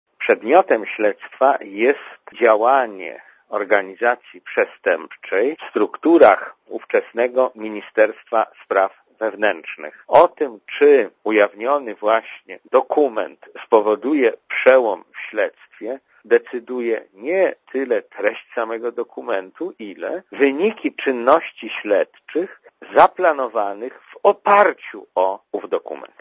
Komentarz audio
kuleszaonotatce.mp3